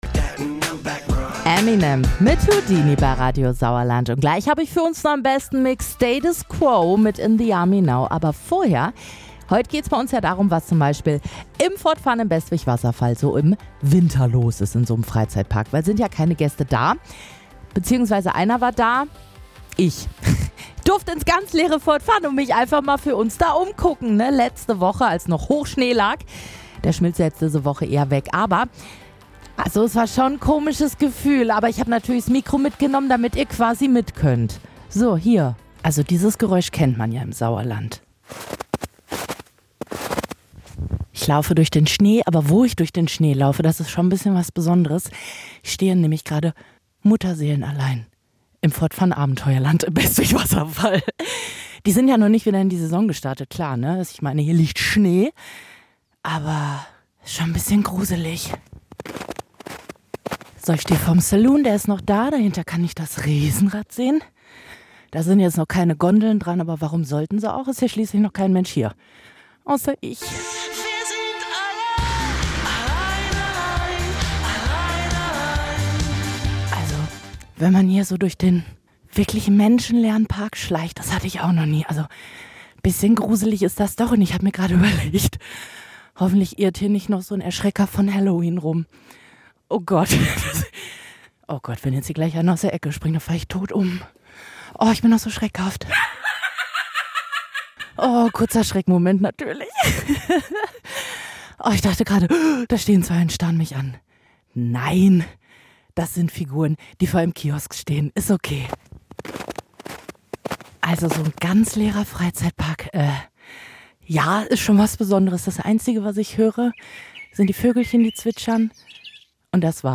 Wir waren vor Ort und haben uns den Freizeitpark im Winterschlaf angeschaut.